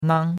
nang1.mp3